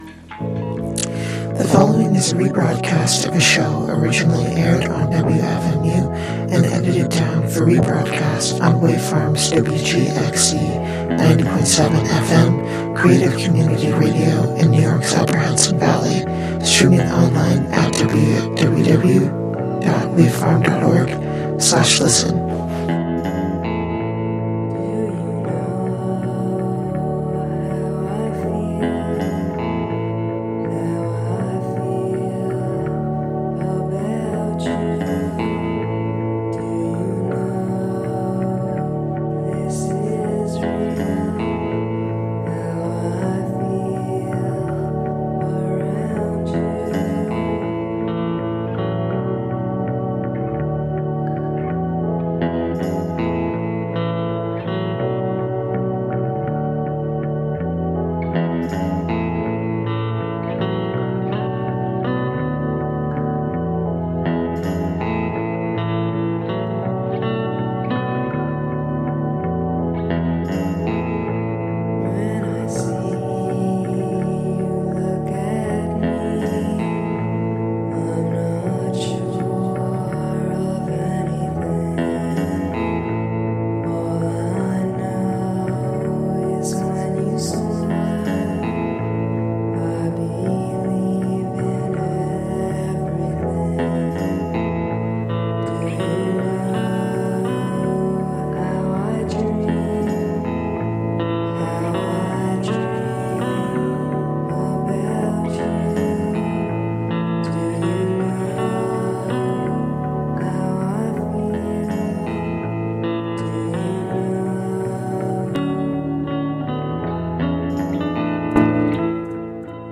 In a stew of intimate electronics, bad poetry, and tender murk, we swap tongues and reach for more.